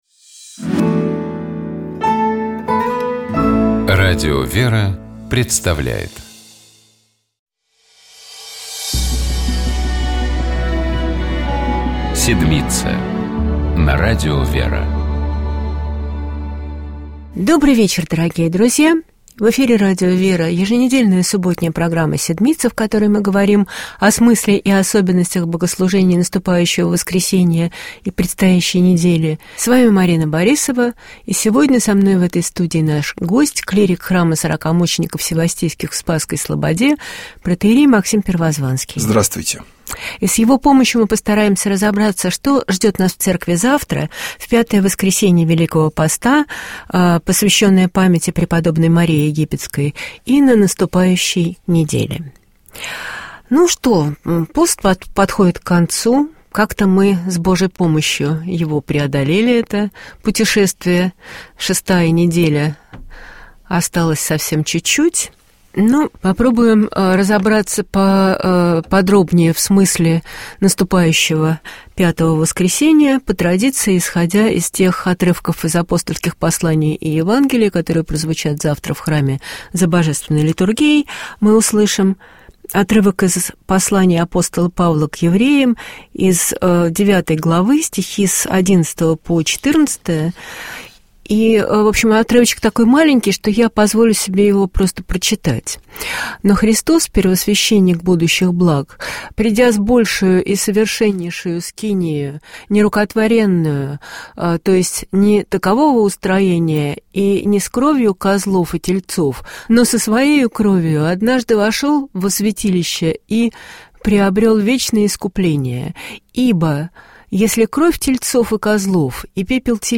Гость программы — кандидат искусствоведения, доцент Московский государственный университет технологий и управления имени